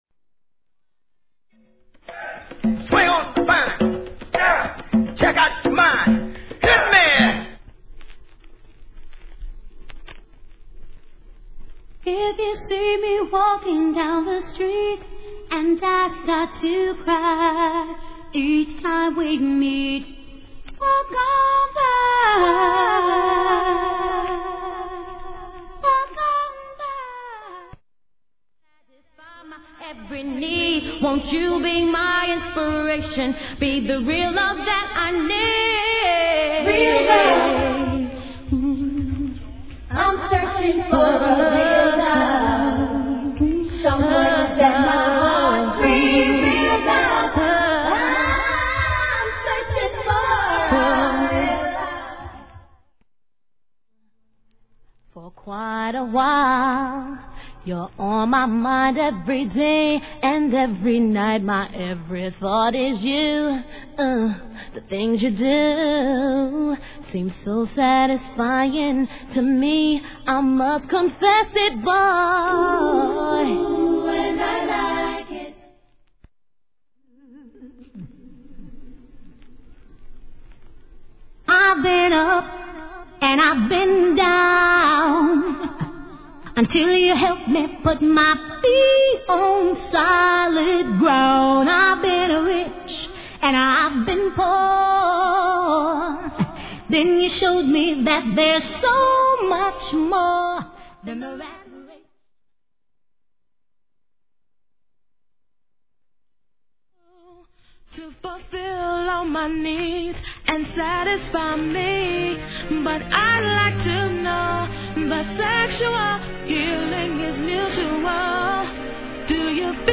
HIP HOP/R&B
R&B CLASSICアカペラ集♪ A1.